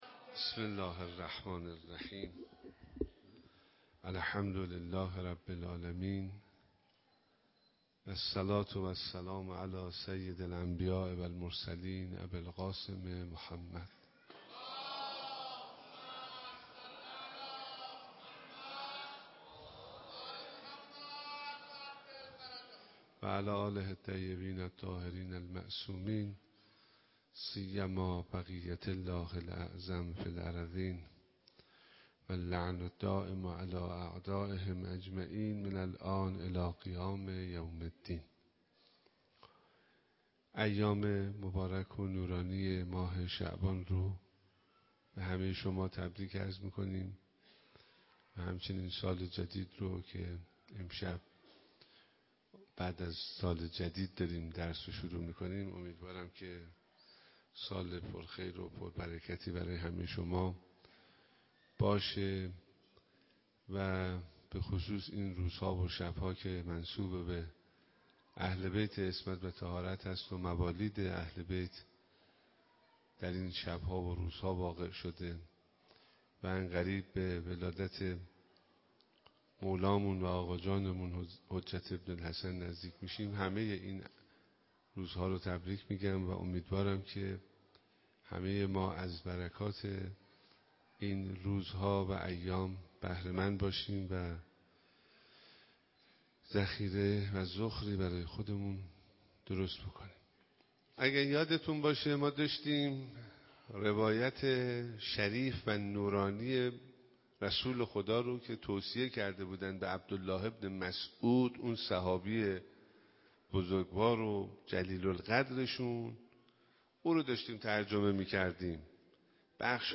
درس اخلاق آیت‌الله حسینی همدانی، نماینده رهبر معظم انقلاب در استان و امام‌جمعه کرج با حضور اقشار مختلف مردم در مصلا بزرگ امام خمینی (ره) کرج برگزار شد